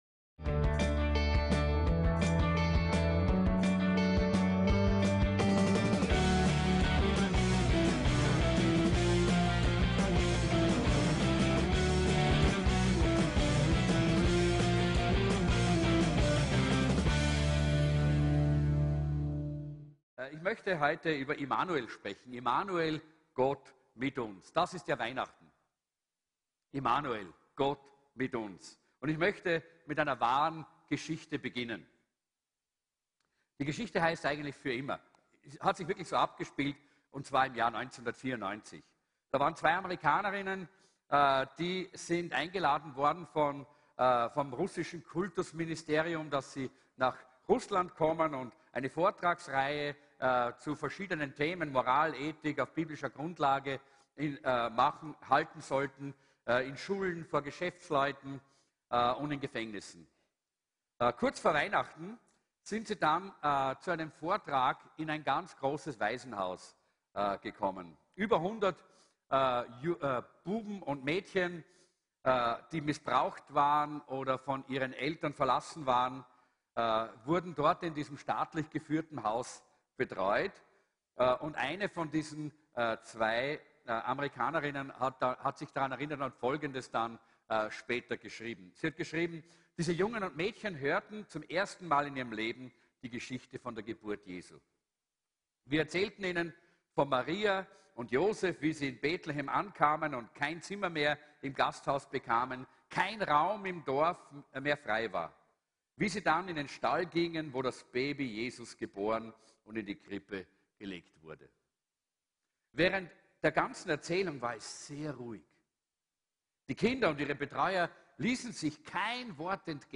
VCC JesusZentrum Gottesdienste (audio) Podcast